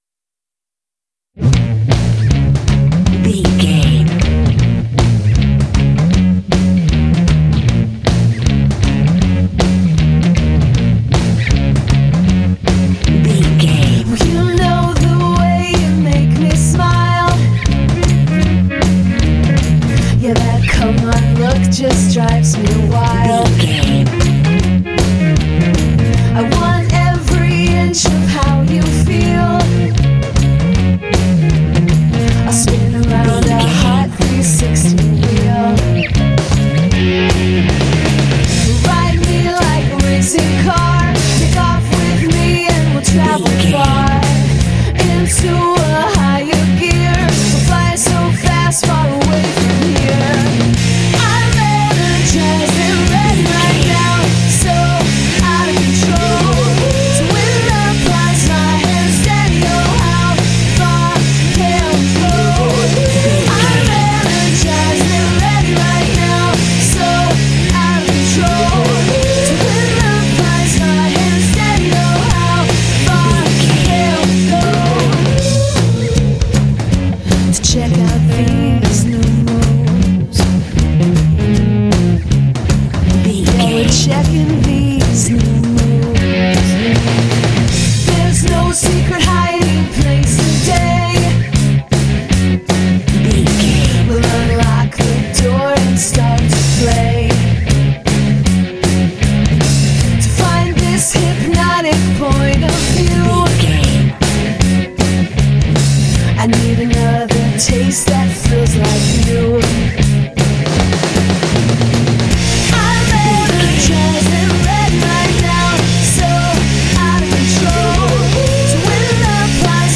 Aeolian/Minor
B♭
driving
energetic
sultry
aggressive
drums
electric guitar
bass guitar
vocals
alternative rock
indie